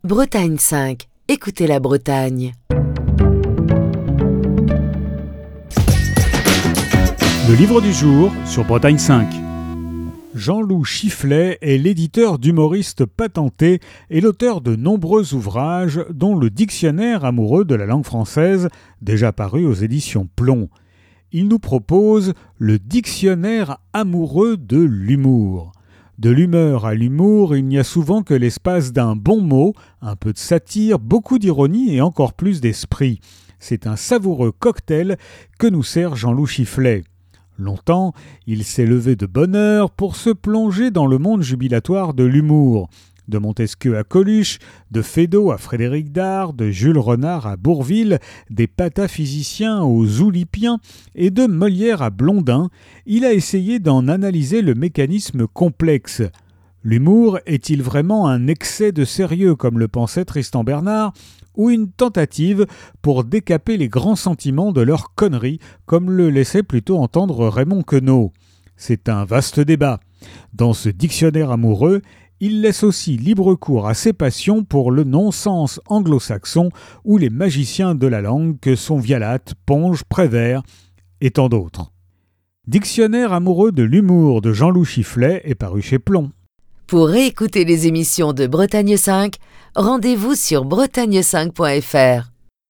Chronique du 1er décembre 2022.